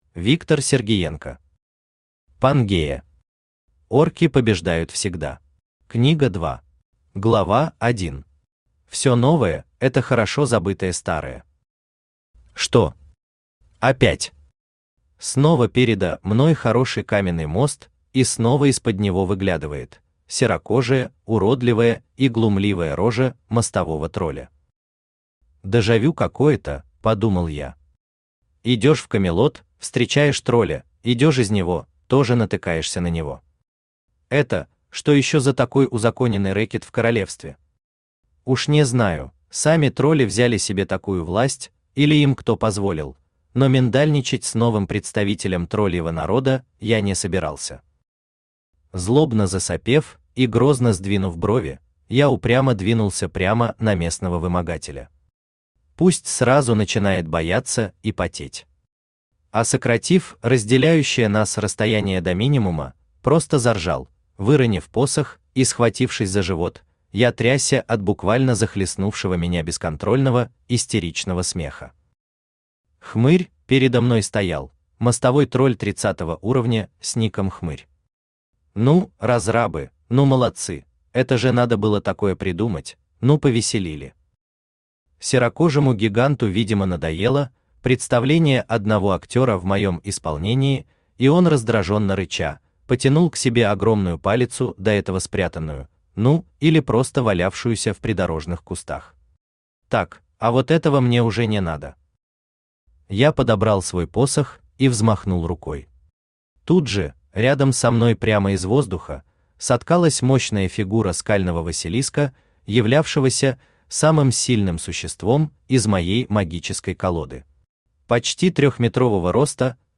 Аудиокнига Пангея. Орки побеждают всегда. Книга 2 | Библиотека аудиокниг
Книга 2 Автор Виктор Николаевич Сергиенко Читает аудиокнигу Авточтец ЛитРес.